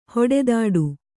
♪ hoḍdāḍu